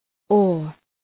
Προφορά
{ɔ:}